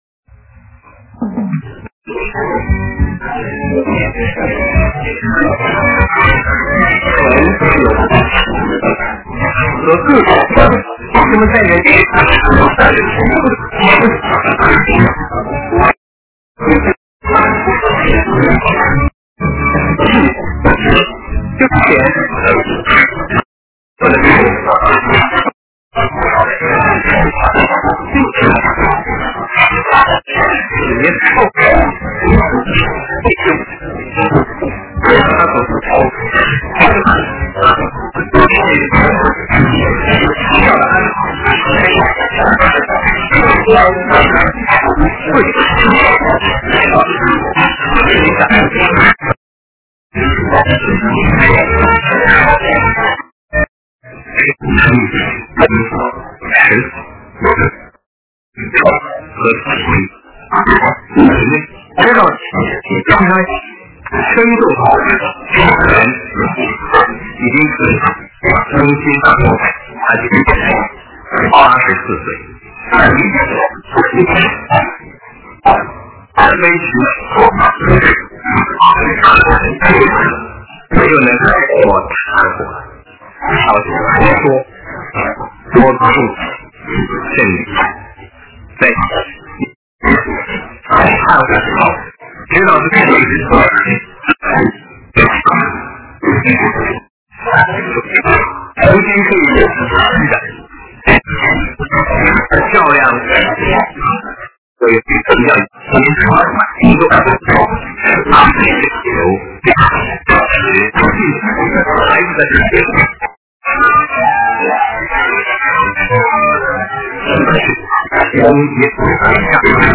人生讲座:老年人的需要